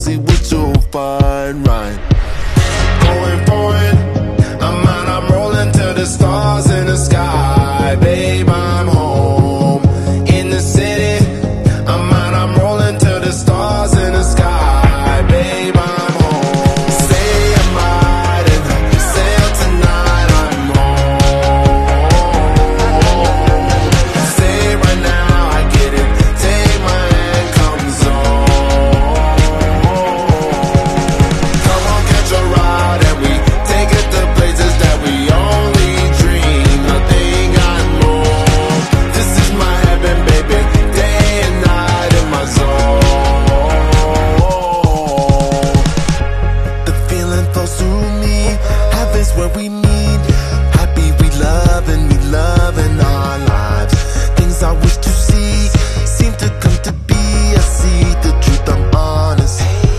Love These ASMR Sticks!